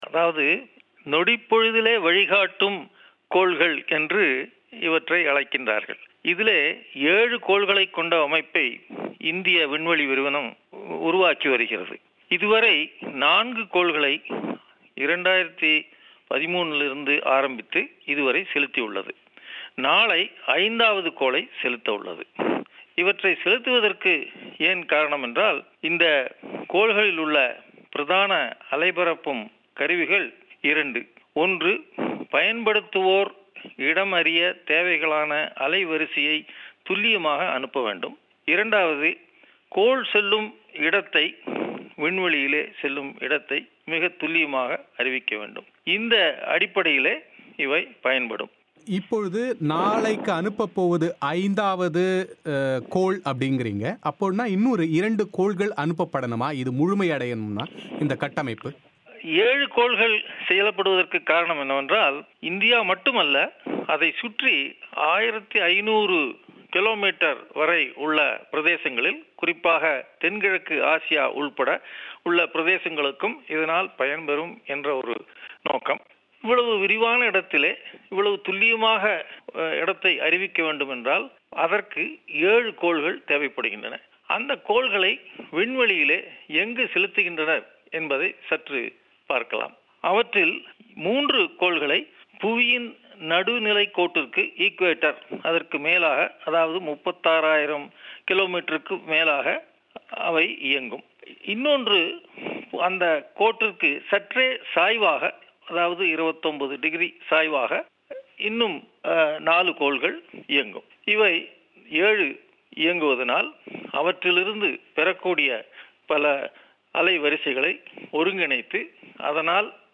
அவர் பிபிசி தமிழோசைக்கு அளித்த விரிவான செவ்வியை நேயர்கள் இங்கே கேட்கலாம்.